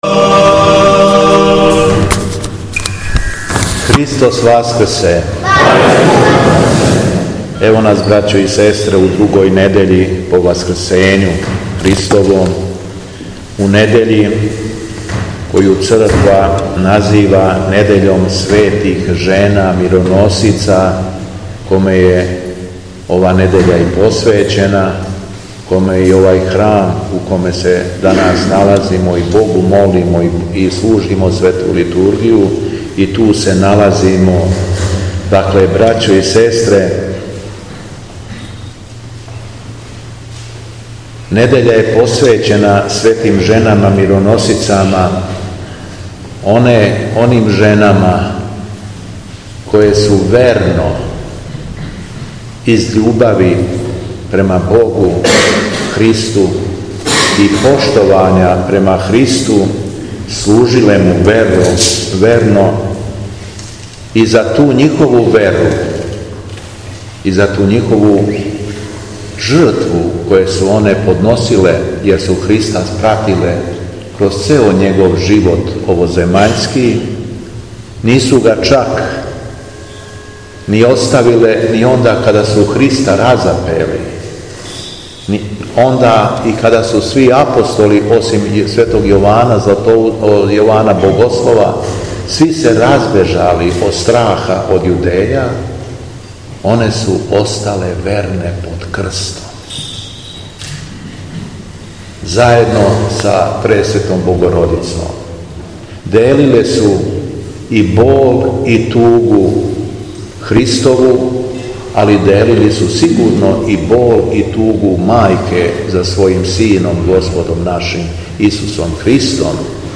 СВЕТЕ ЖЕНЕ МИРОНОСИЦЕ – ХРАМОВНА СЛАВА У ВИНЧИ - Епархија Шумадијска
Беседа Његовог Преосвештенства Епископа шумадијског г. Јована
После прочитаног јеванђелског зачала беседио је владика Јован: